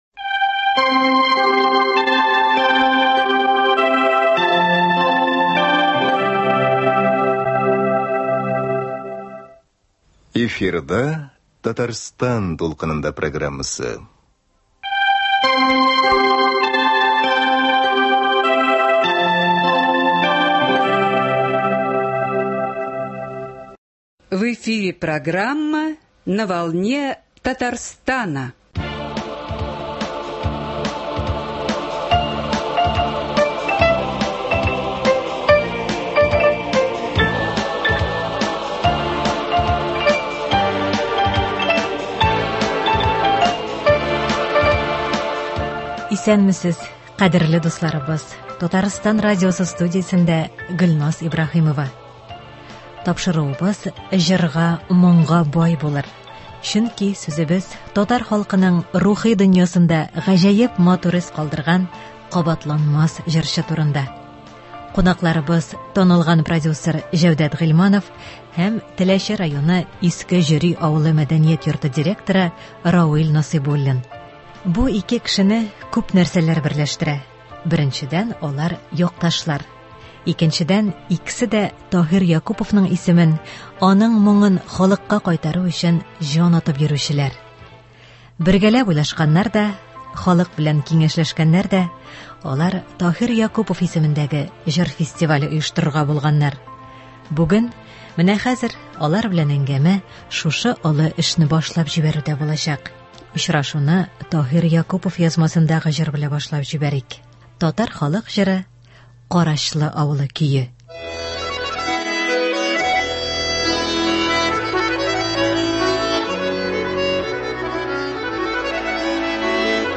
Тапшыруыбыз җырга-моңга бай булыр, чөнки сүзебез татар халкының рухи дөньясында гаҗәеп матур эз калдырган кабатланмас җырчы турында.